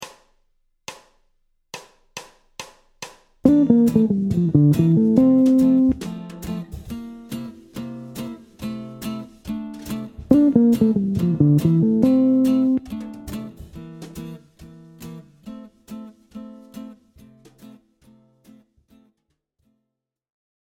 Une phrase d’Emily Remler sur l’accord de dominante qui descend le mode de C Mixolydien à partir de la Fondamentale